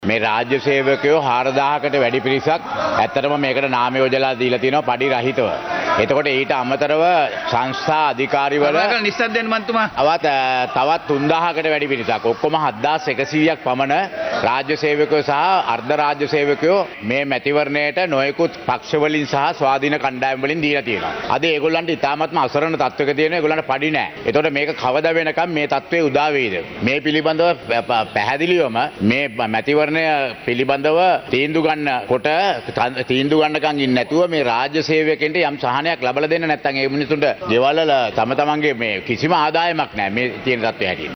මැතිවරණය පිළිබඳ පාර්ලිමේන්තුවේදී ඉදිරිපත් වූ අදහස්